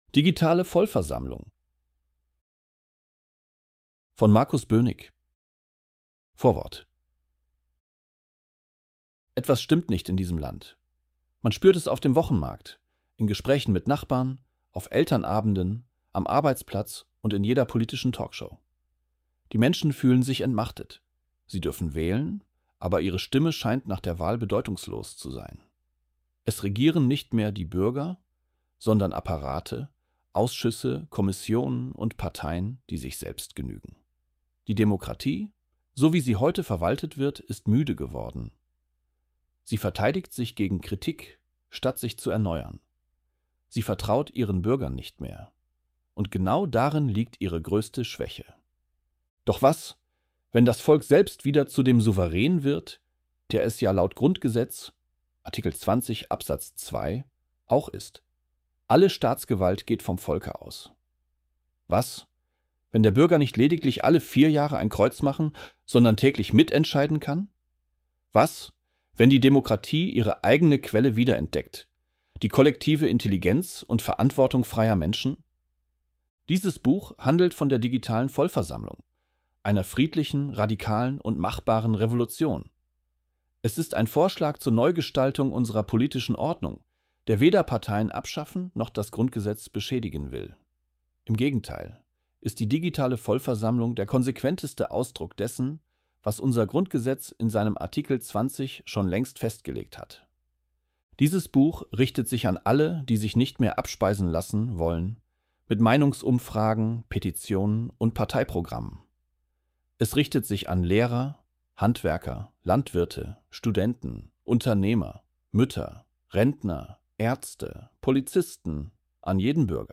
ElevenLabs_digitale_Vollversammlung.mp3